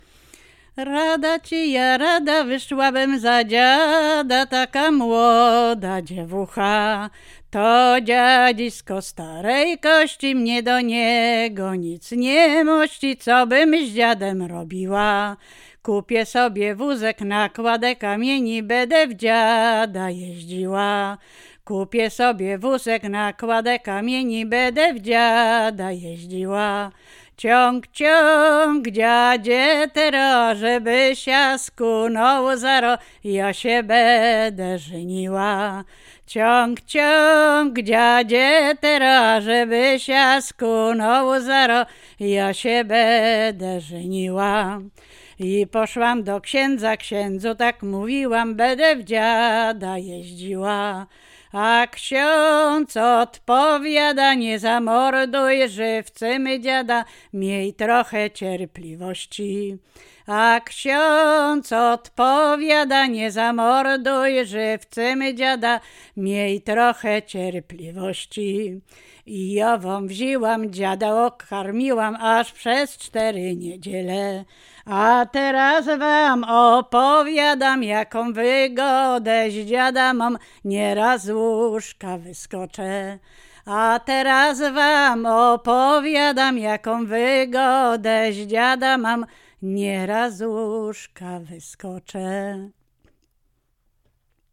Łęczyckie
województwo łódzkie, powiat kutnowski, gmina Bedlno, wieś Bedlno
liryczne miłosne